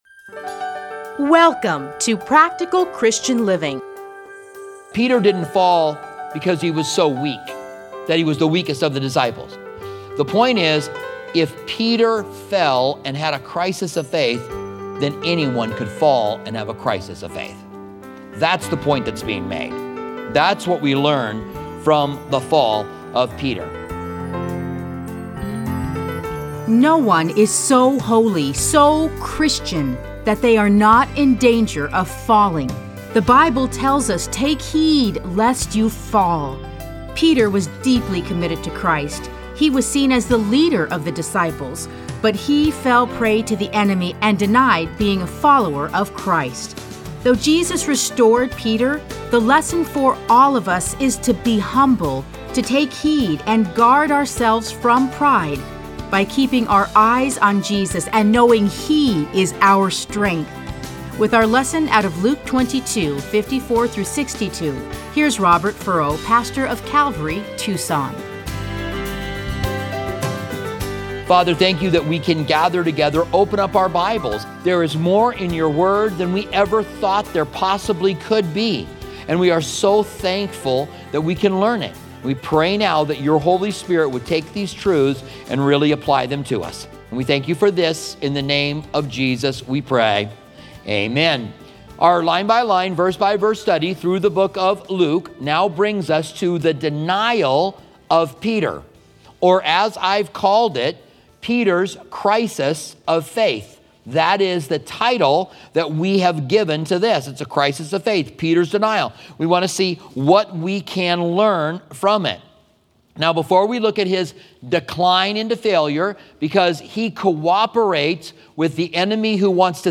Listen to a teaching from Luke 22:54-62.